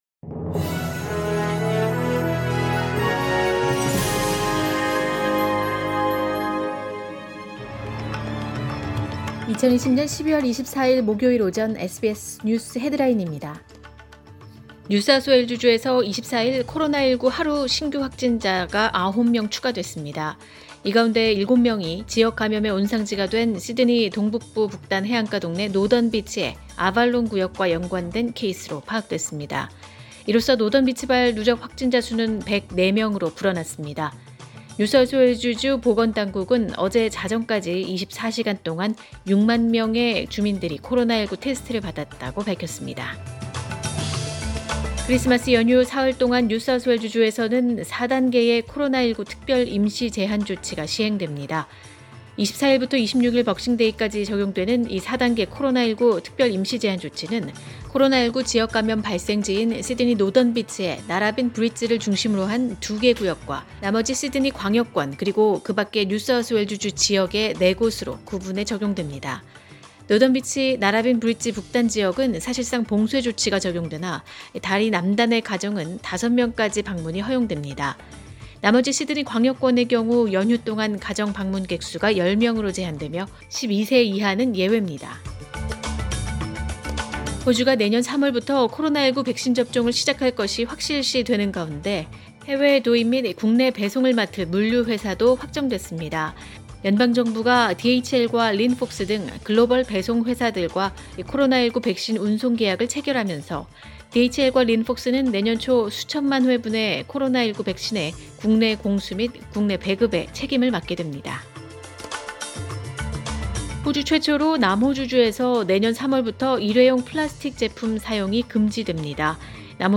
2020년 12월 24일 목요일 오전의 SBS 뉴스 헤드라인입니다.